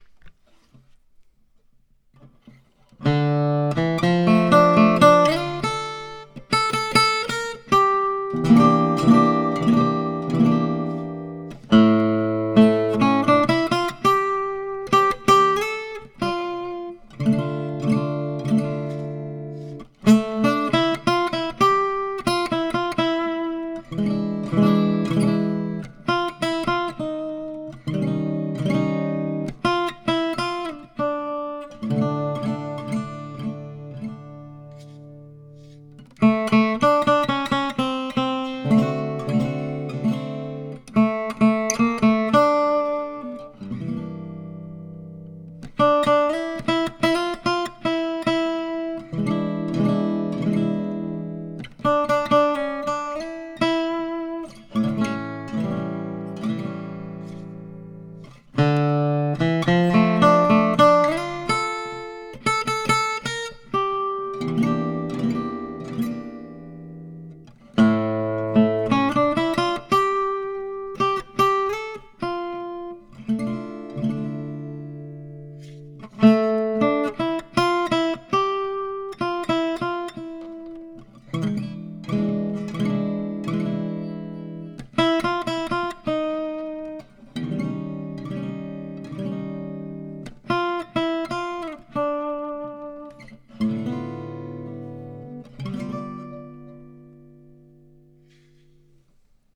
I think the guitar is slightly louder now.
I recorded with the internal mics of my old Tascam DP 008.